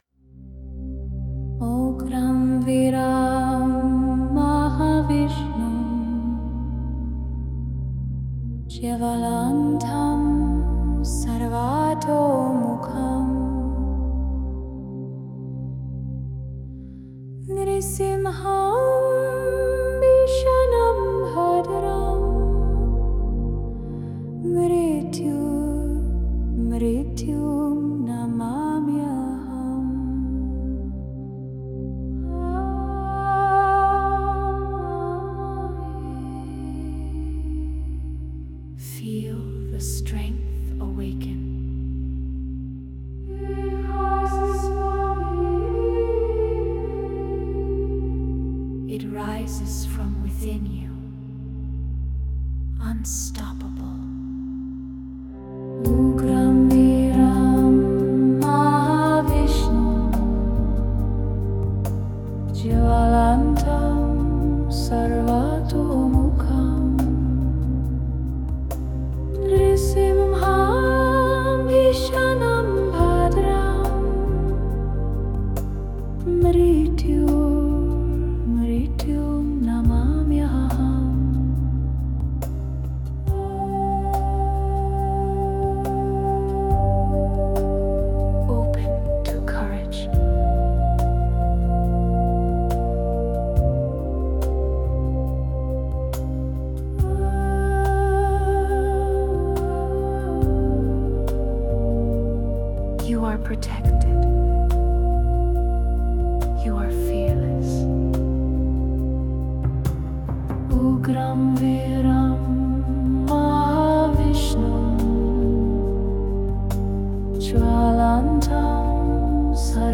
Erdige Percussion trifft auf atmosphärische Klangräume.